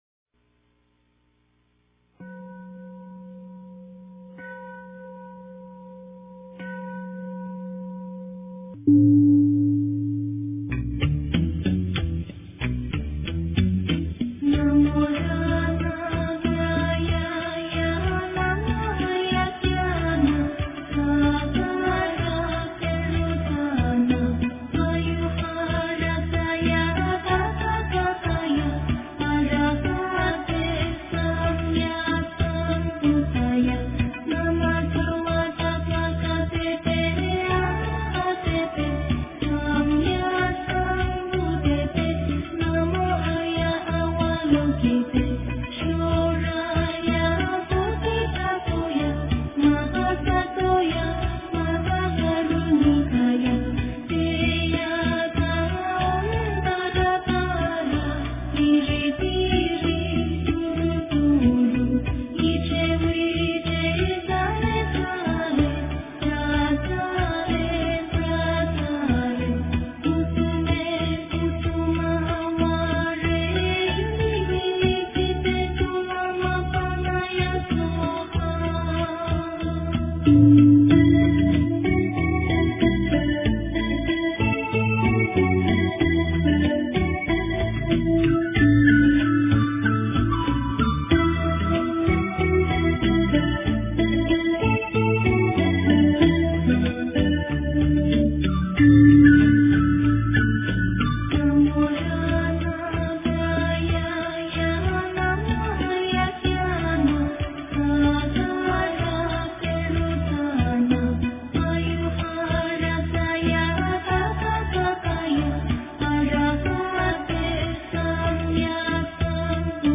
大悲咒 诵经 大悲咒--女声轻柔版 点我： 标签: 佛音 诵经 佛教音乐 返回列表 上一篇： 般若波罗蜜多心经 下一篇： 释迦牟尼佛传45 相关文章 观世音菩萨白佛言--如是我闻 观世音菩萨白佛言--如是我闻...